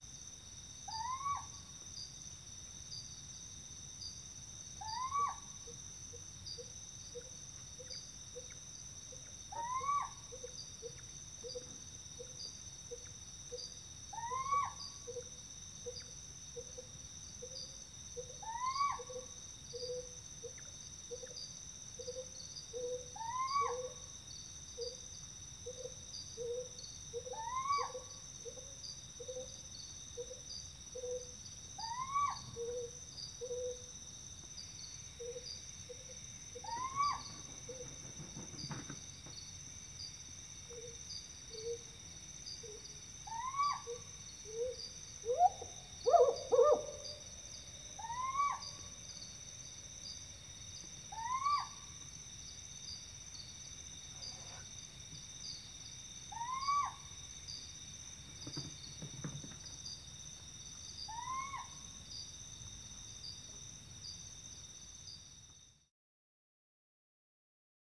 4-50-Humes-Owl-Presumed-Feeding-Call-Compound-Hoot-Of-Male-With-Soliciting-Calls-Of-Female.wav